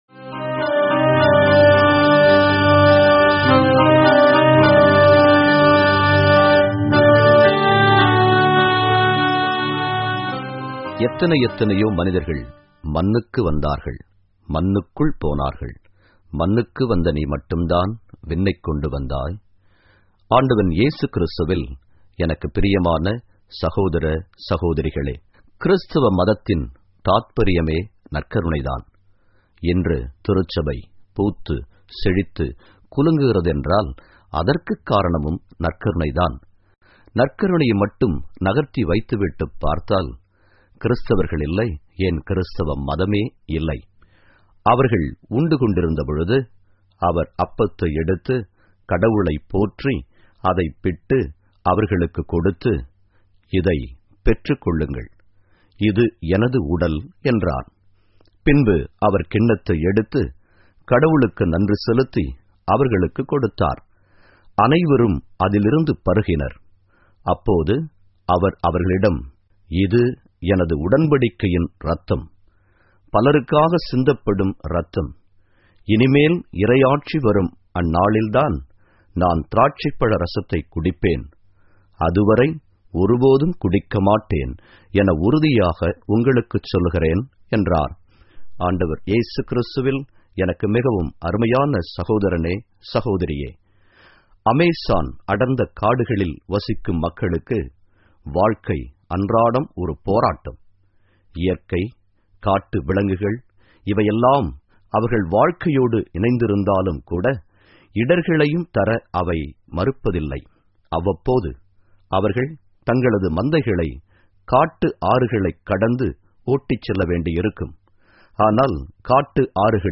Homilies Lenten